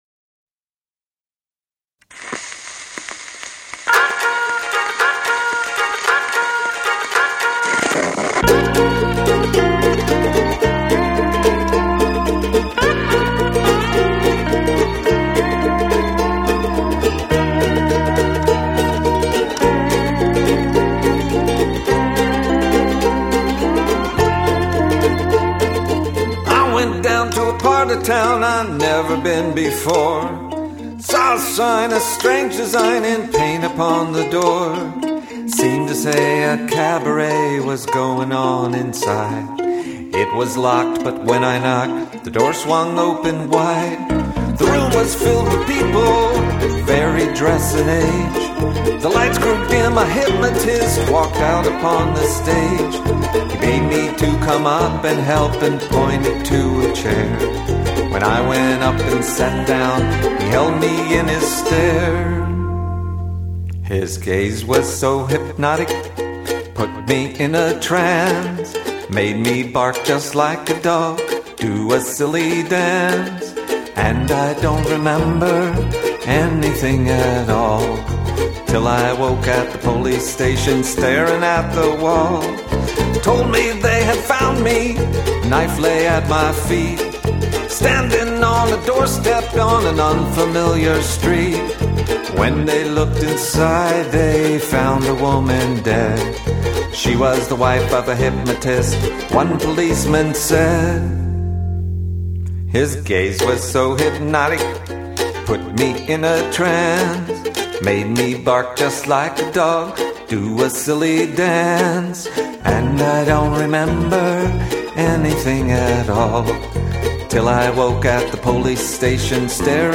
Ukulele Soiree